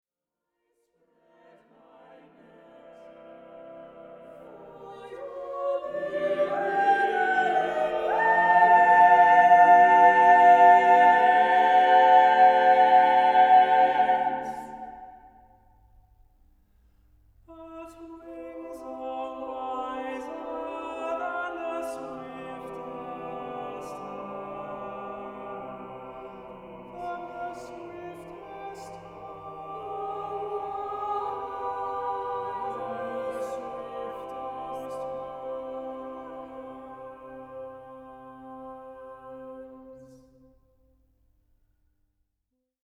an a cappella setting of a mystical poem